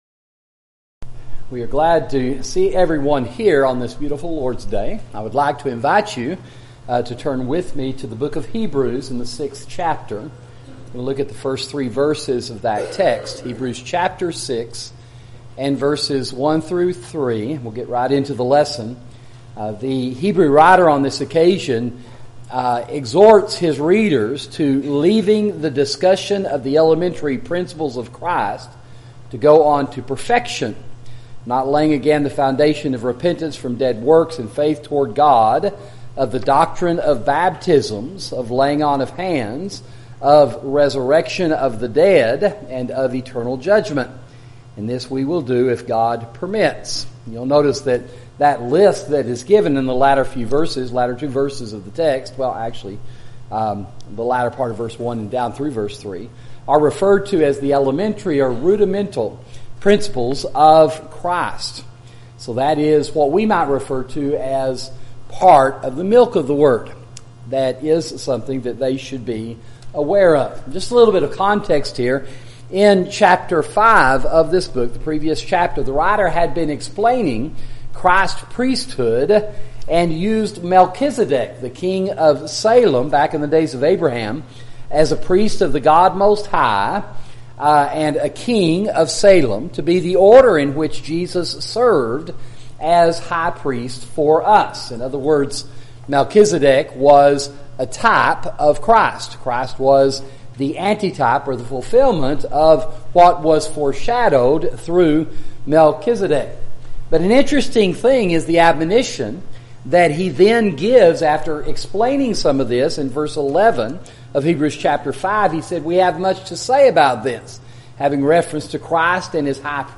Sermon: Elementary Principles